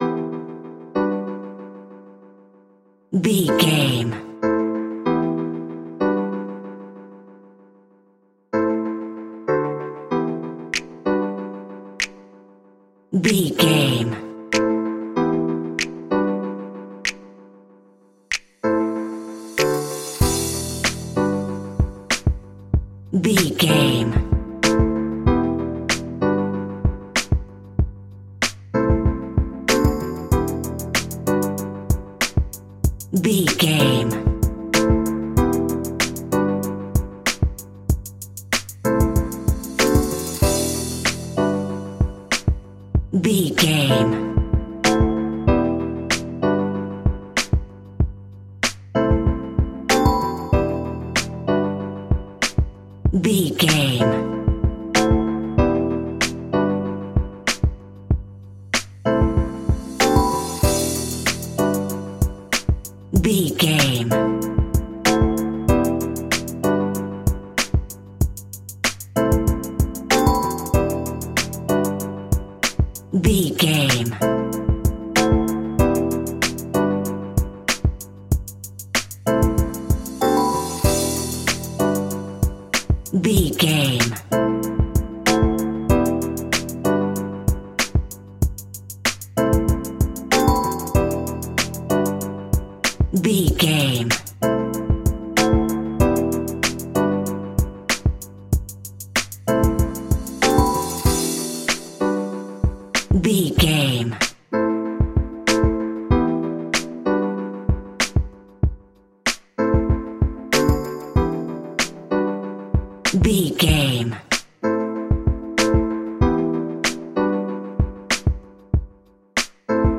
Upbeat Urban Alt.
Aeolian/Minor
chilled
laid back
groove
hip hop drums
hip hop synths
piano
hip hop pads